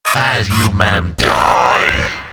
Android Infantry (Sound Voice)
The compilation contains 17 sounds, and here are samples of the android's best dialogue.
android_attack3_125.wav